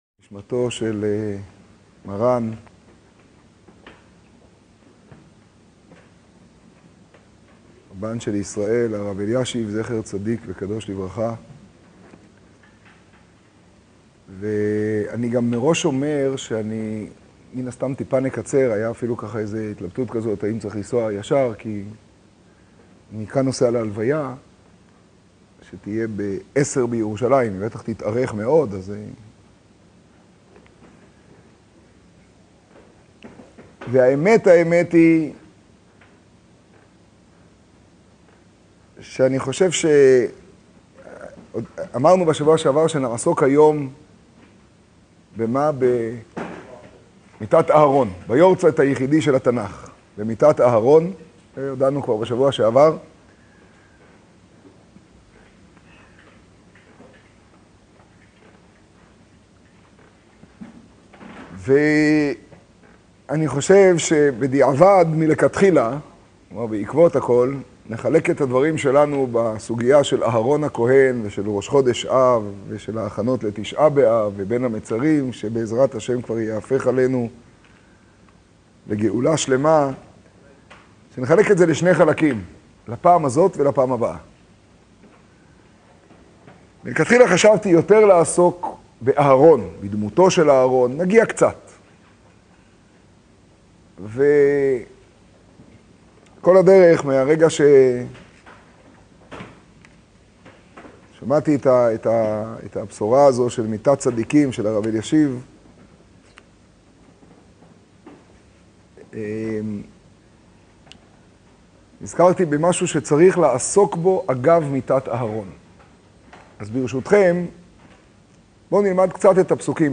שיעורי הרב מרדכי אלון שליט"א
השיעור ברעננה כח תמוז תשעב, ביום הסתלקותו של מרן הרב אלישיב זי"ע. להזנה